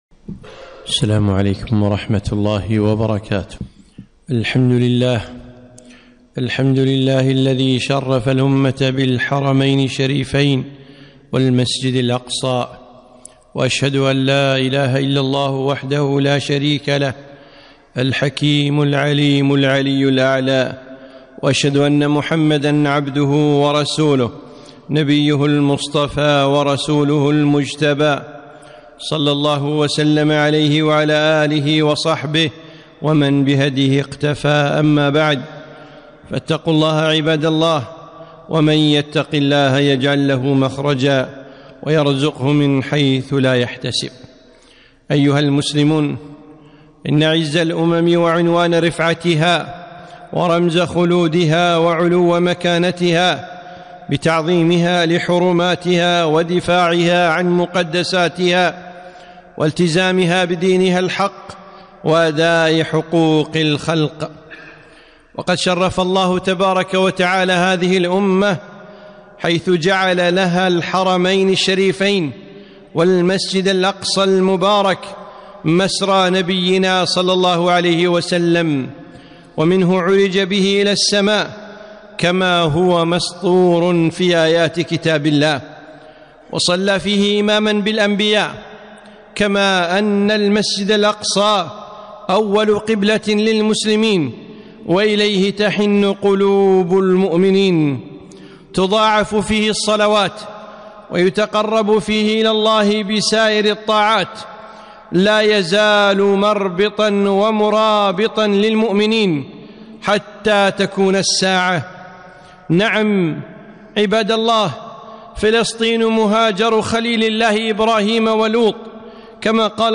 خطبة - نصر الله قريب - دروس الكويت